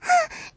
moan2.ogg